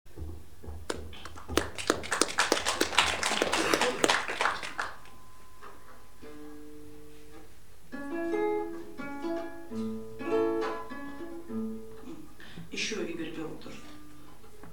Запись первых трёх часов "Праздника Самой Длинной Ночи" в ЦАПе 22.12.2002